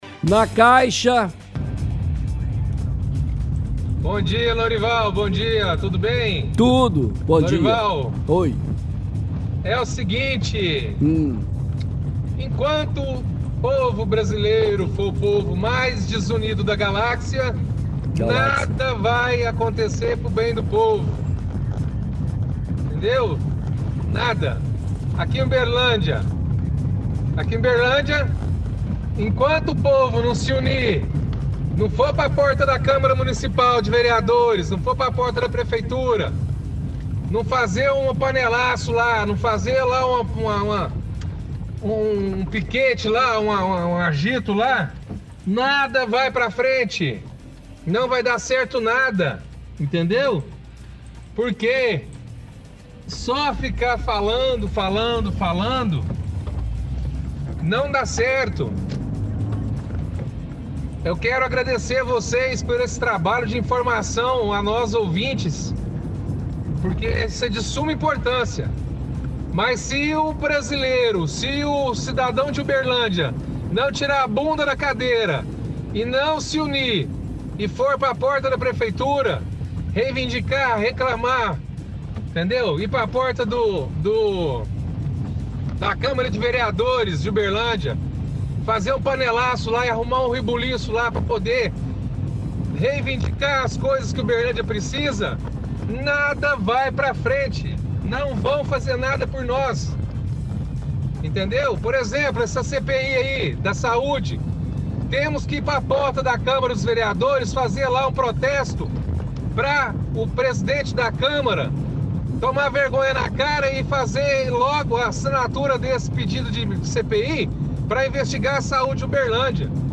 – Ouvinte reclama que o povo é desunido e enquanto a população não fizer protestos na porta da câmara e da prefeitura, nada vai acontecer.